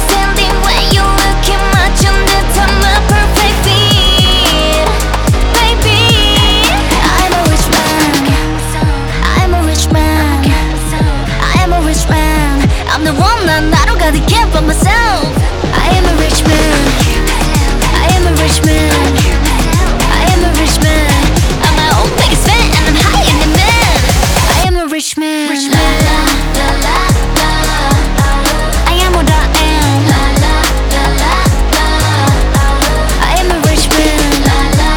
Жанр: Поп музыка
K-Pop, Pop